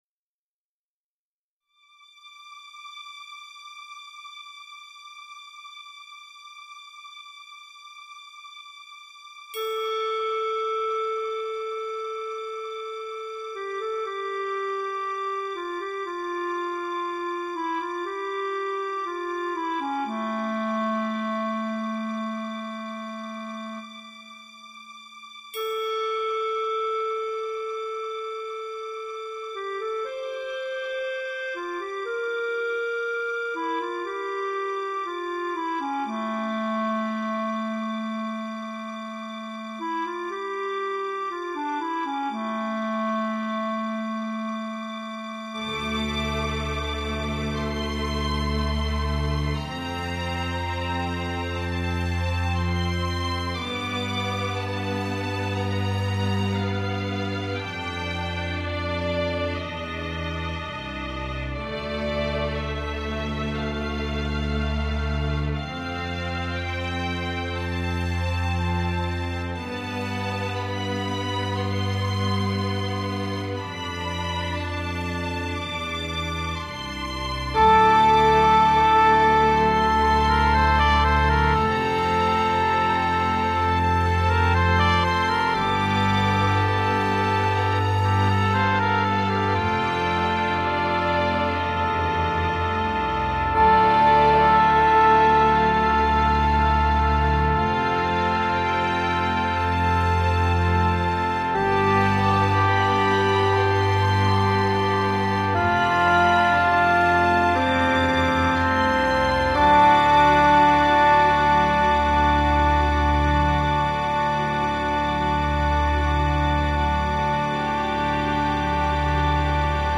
Classical and jazz compositions of my own: